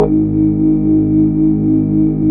SYN KRAFTA03.wav